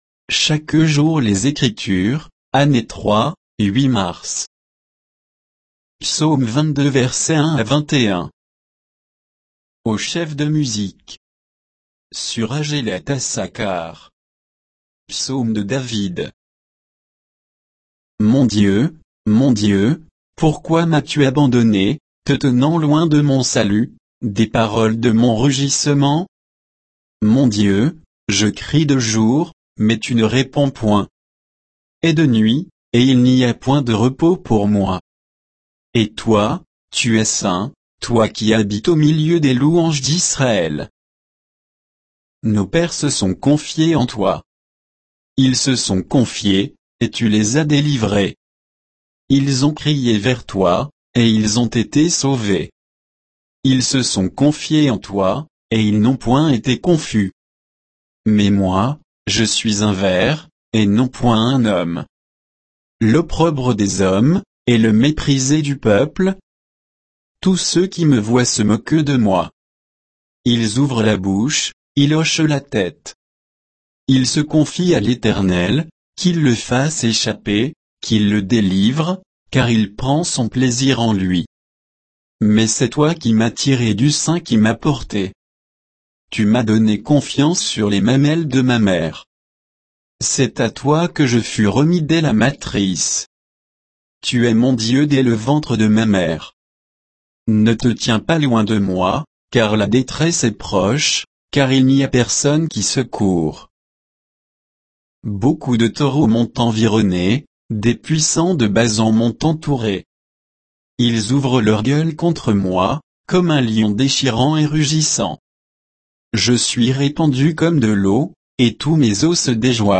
Méditation quoditienne de Chaque jour les Écritures sur Psaume 22